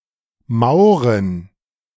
Mauren (German pronunciation: [ˈmaʊ̯ʁən]